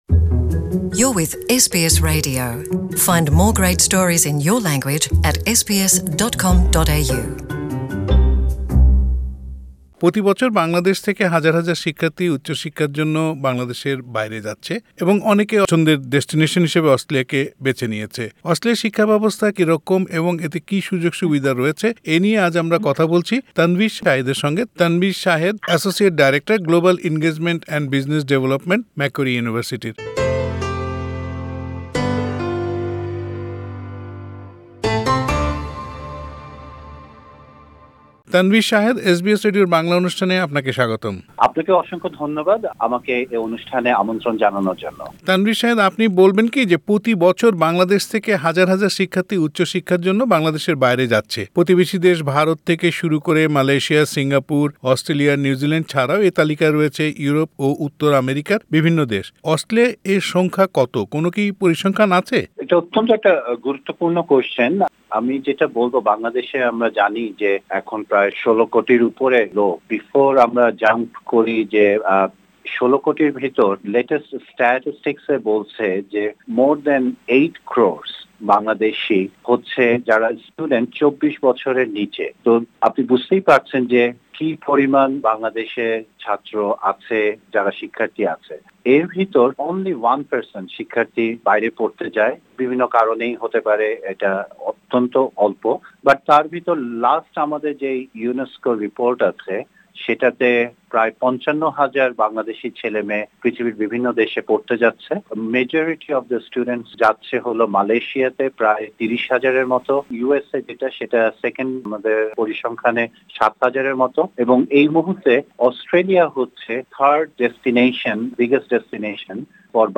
অস্ট্রেলিয়ায় বাংলাদেশের শিক্ষার্থীদের পড়াশোনার সুযোগ-সুবিধা নিয়ে এসবিএস বাংলার সঙ্গে কথা বলেন